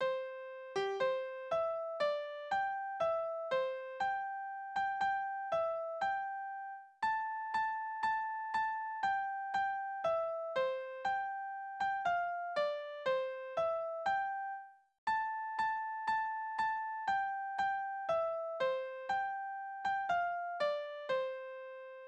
Reservistenlieder: Garnison
Tonart: C-Dur
Taktart: 2/4
Tonumfang: große None
Besetzung: vokal
Anmerkung: Vortragsbezeichnung: Marschtempo; eine falsche Taktart vorgezeichnet (4/4)